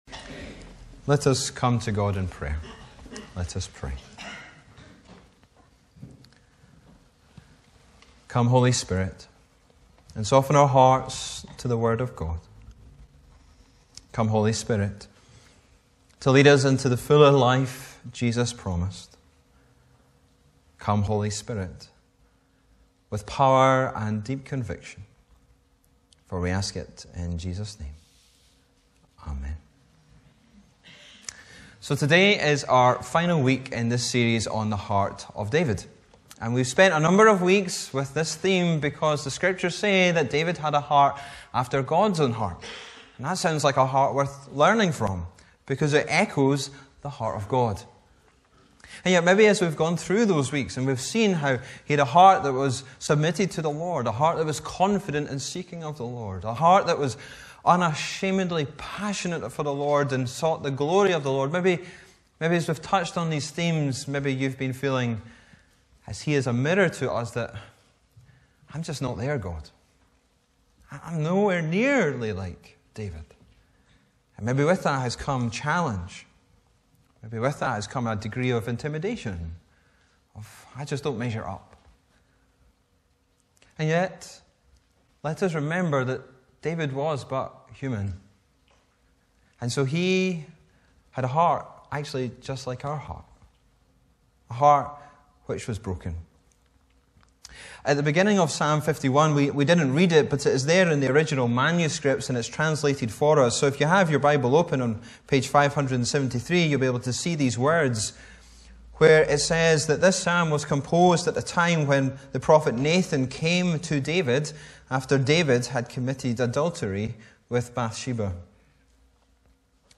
Bible references: Psalm 51:1-12 Location: Brightons Parish Church Show sermon text Sermon keypoints: - Our hearts are broken - God is ready to give life - God is ready to mend our hearts Broken and restored heart Heart for God’s Glory Heart of Passion Confident and seeking heart Submitted heart